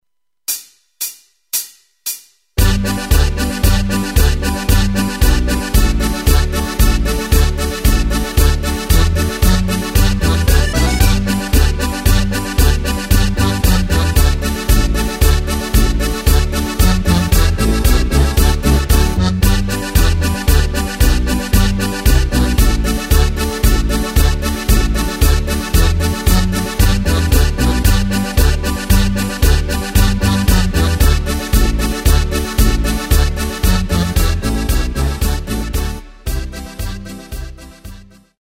Takt:          2/4
Tempo:         114.00
Tonart:            G
Schweizer Ländler/Polka!
Playback mp3 Demo